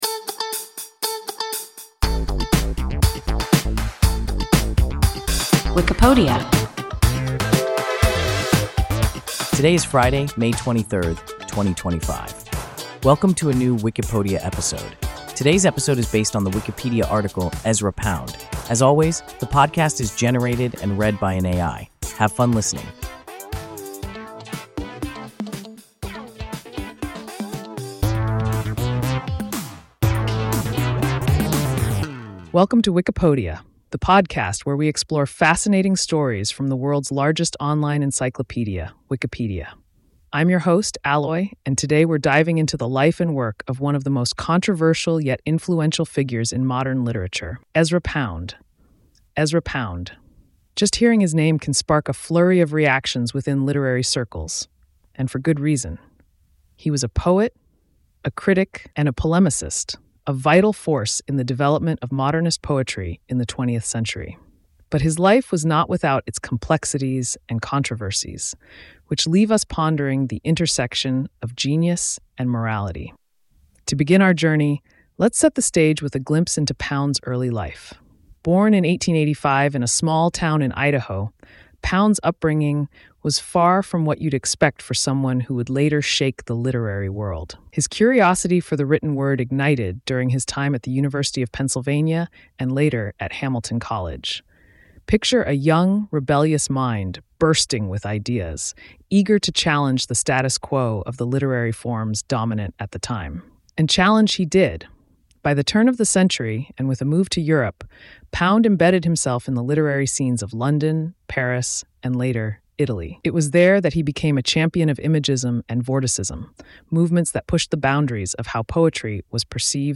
Ezra Pound – WIKIPODIA – ein KI Podcast